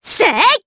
Cecilia-Vox_Attack3_jp.wav